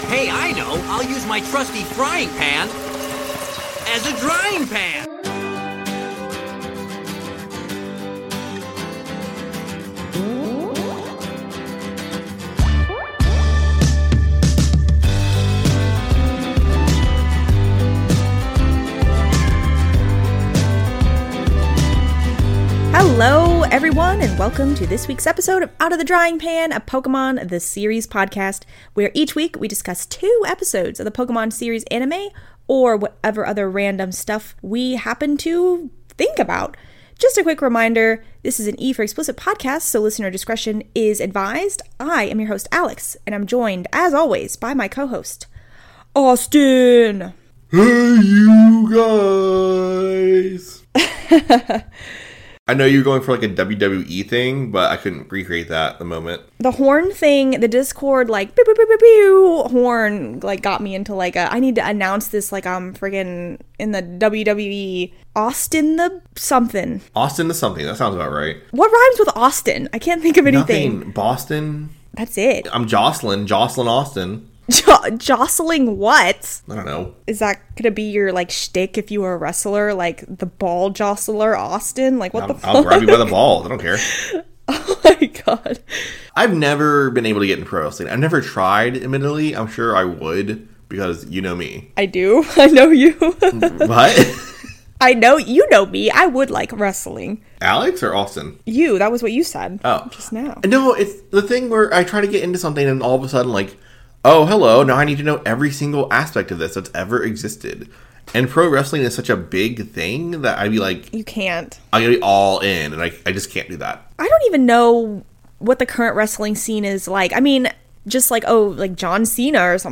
A podcast where three filthy casual Millennials look back at the English dub of Pokémon the Series, and pick it apart relentlessly.